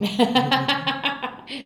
LAUGH 3.wav